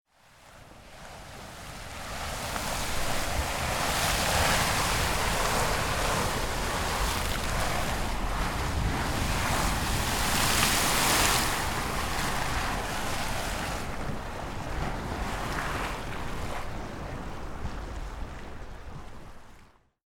Witness an eruption of Cliff Geyser in the Black Sand Basin. Mono recording.